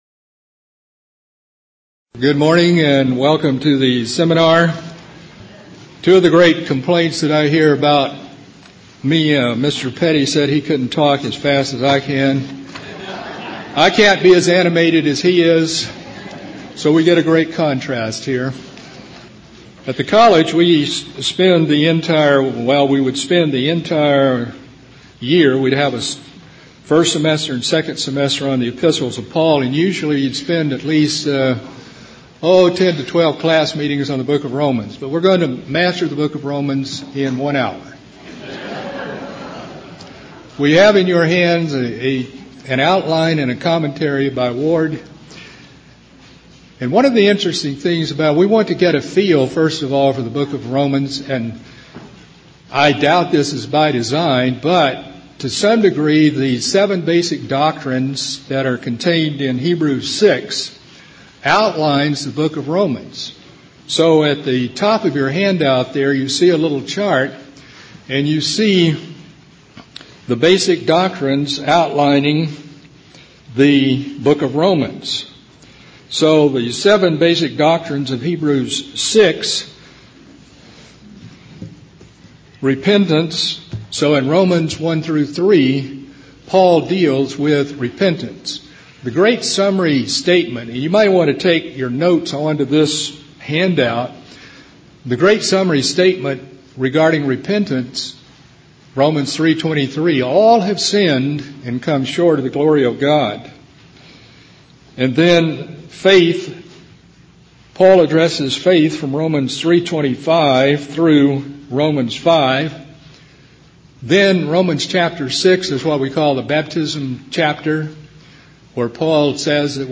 Kerrville 2009 Feast of Tabernacles Seminar. Outline of the ABC class on Romans.